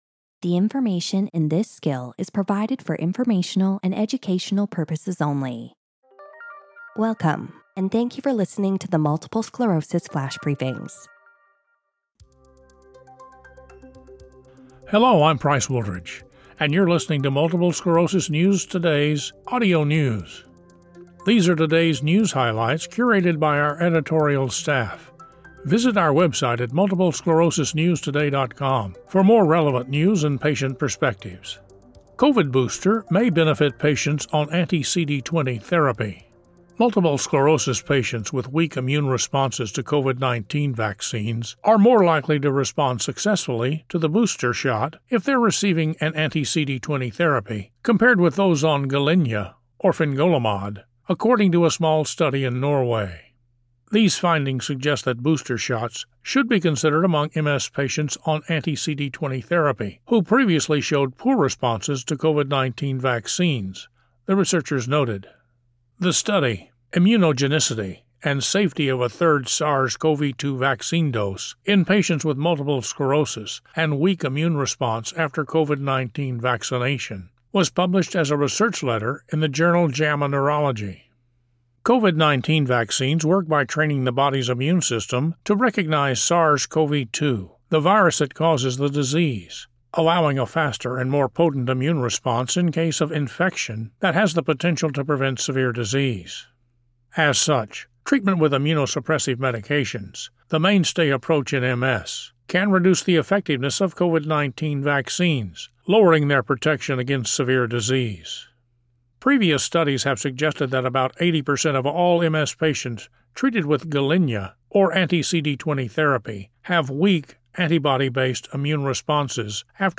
reads about how MS patients with weak COVID-19 vaccine responses and receiving an anti-CD20 therapy are likely to respond favorably to the booster shot